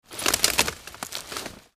Here's a few pics from a recent audio capture session I did up in the North woods of Wisconsin. It was a mostly pleasant day, although the birds were rather bothersome background noise wise.
Tools of the Trade: Trusty PowerBook, Rode NTG-2, Sennheiser HD-285, and various implements of noise making.
FallingTree.mp3